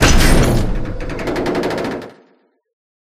Door4.ogg